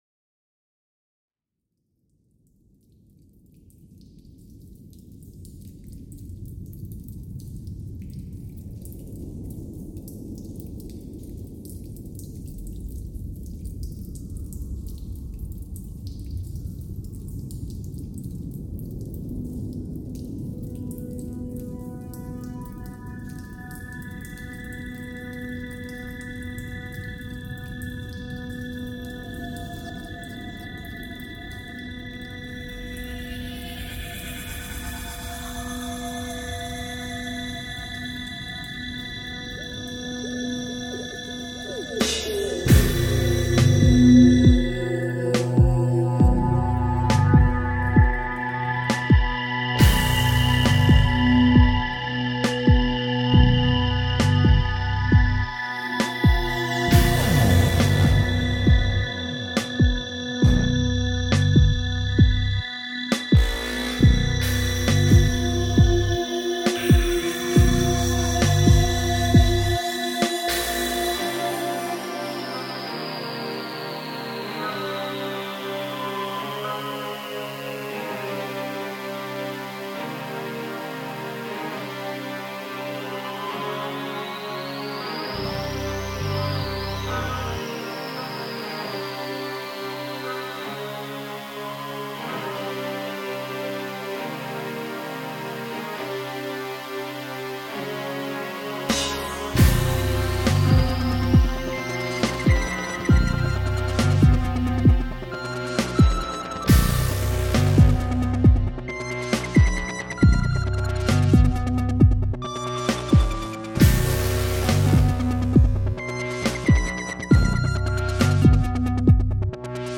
dance/electronic
Hardcore
Trip-hop